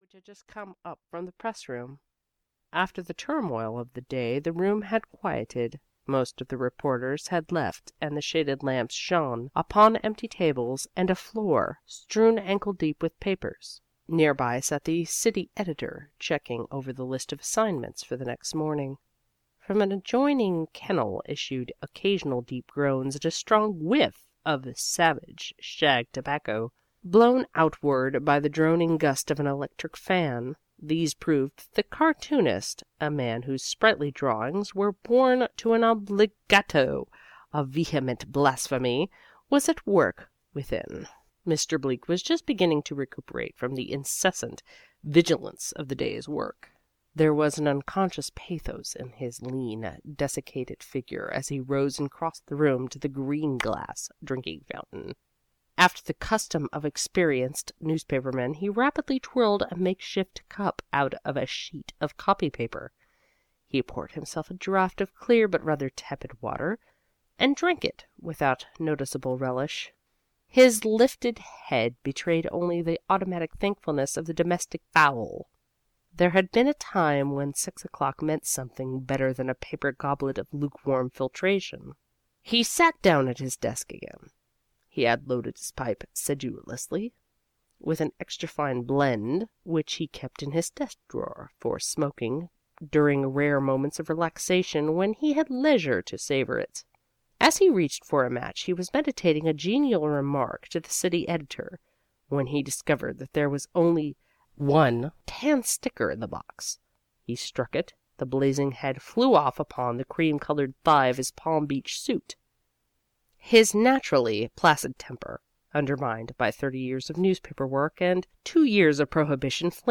In the Sweet Dry and Dry (EN) audiokniha
Ukázka z knihy